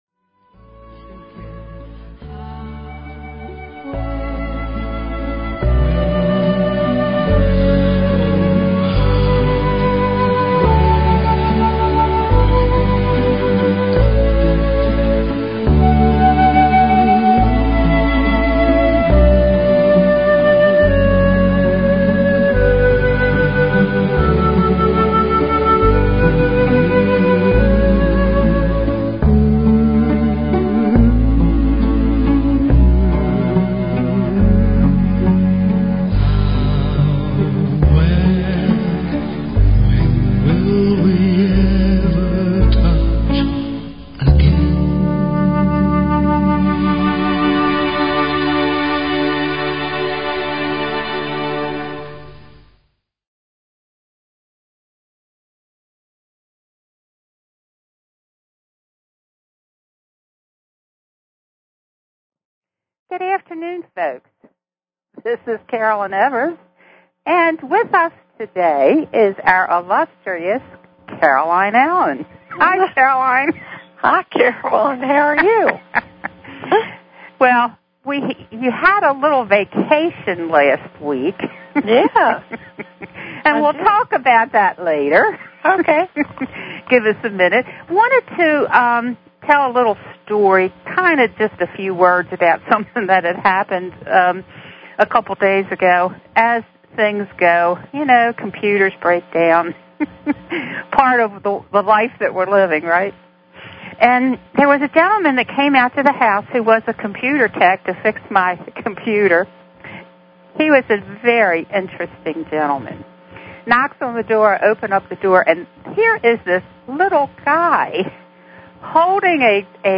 Talk Show Episode, Audio Podcast, The_Message and Courtesy of BBS Radio on , show guests , about , categorized as